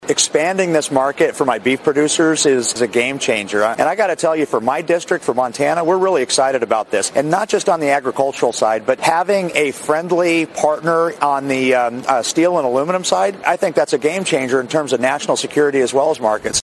Today, Congressman Troy Downing was on Fox Business News talking about the latest trade deal between the United States and the United Kingdom and how he sees it.